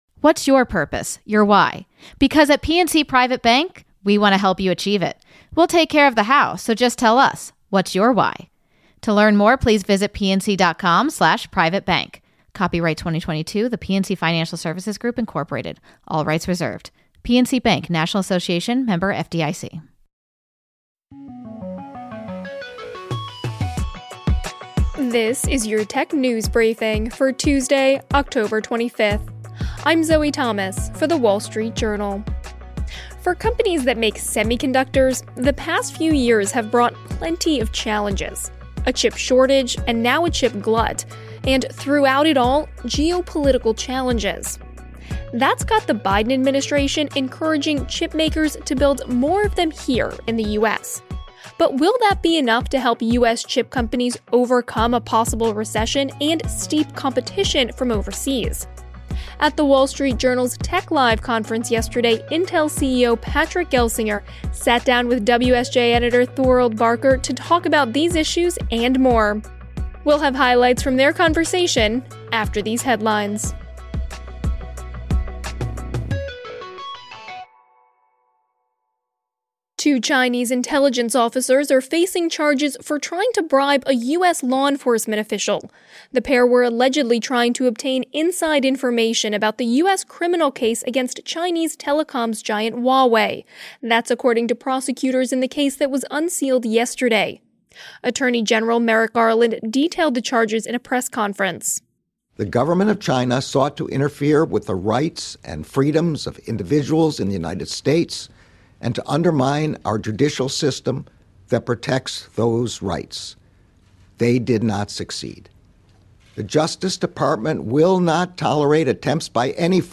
at The Wall Street Journal’s Tech Live conference